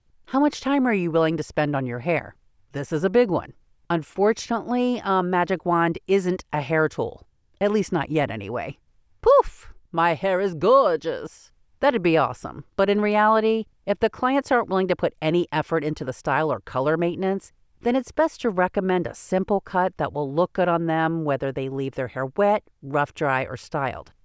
E-Learning
English-Mid Atlantic
Middle Aged